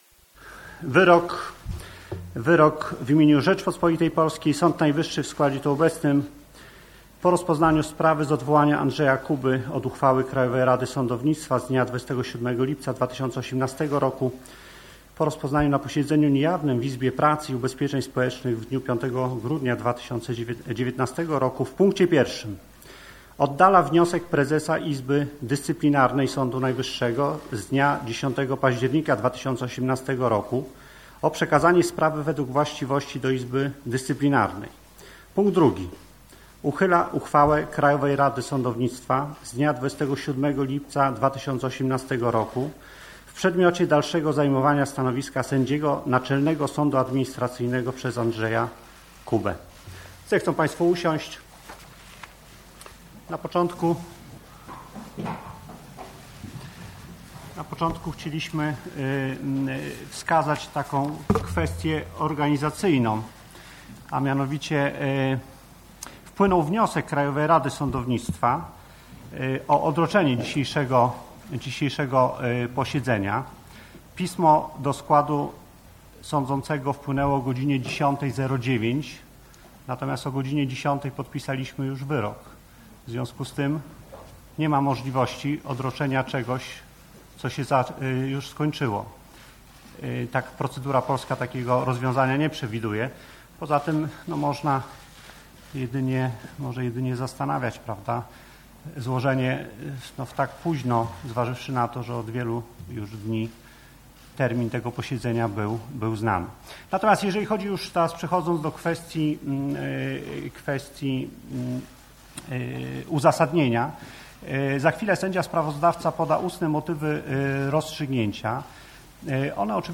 Ustne motywy rozstrzygnięcia, które sąd wygłosił na sali rozpraw, sprowadzają się do ośmiu tez, które przedstawiamy poniżej.